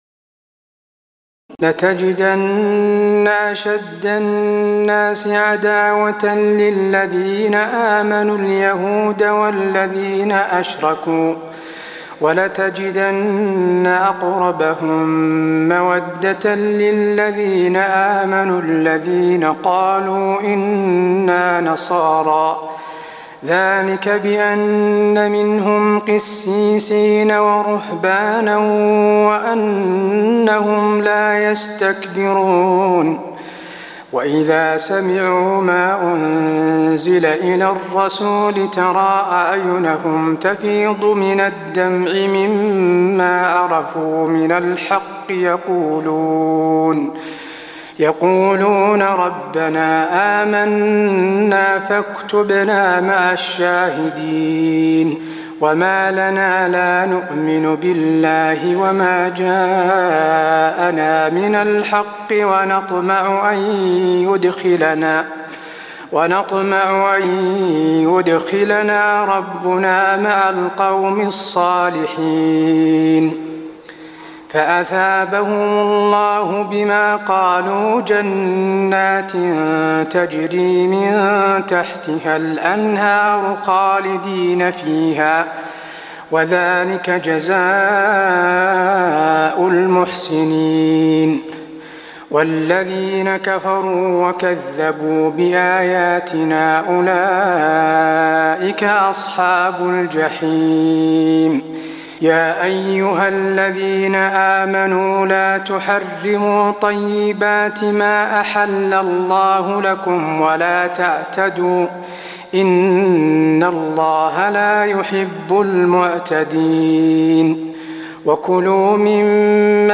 تراويح الليلة السادسة رمضان 1423هـ من سورة المائدة (82-108) Taraweeh 6 st night Ramadan 1423H from Surah AlMa'idah > تراويح الحرم النبوي عام 1423 🕌 > التراويح - تلاوات الحرمين